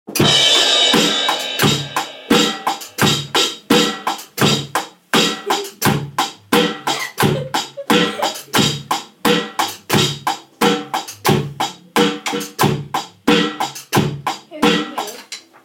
basic drum beat